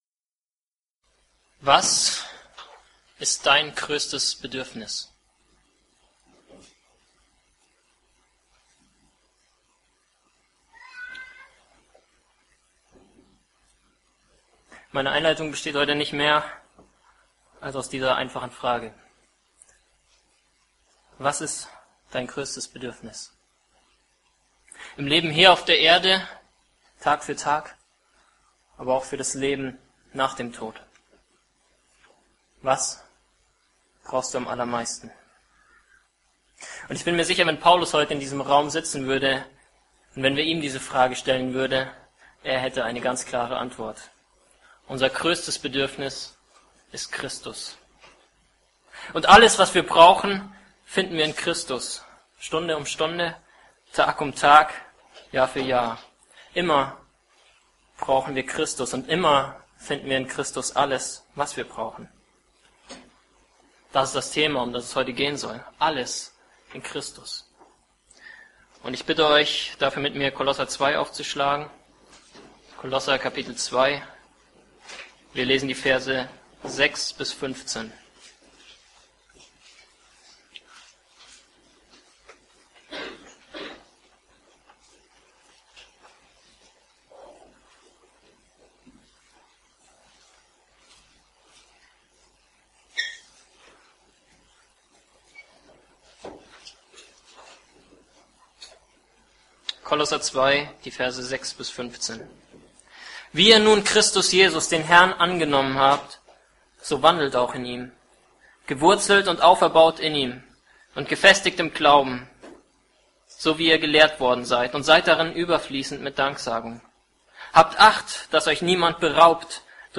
Alles in Christus: Predigt zu Kol 2,6-15 | Josia – Truth for Youth